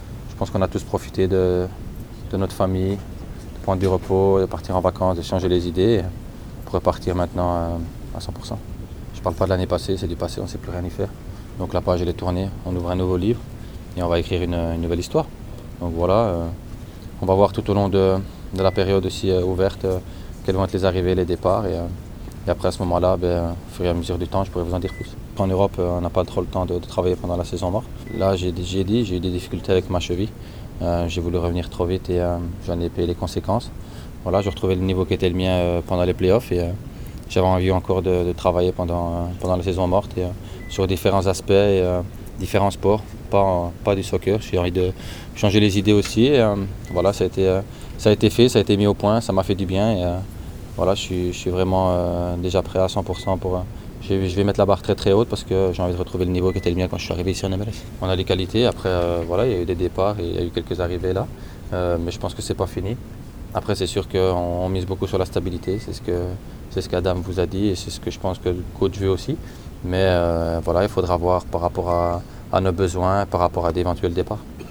Stagione 2017: prospettive e considerazioni dei protagonisti all’assemblea dei membri e presentazione della seconda maglia per la stagione 2017
Le interviste: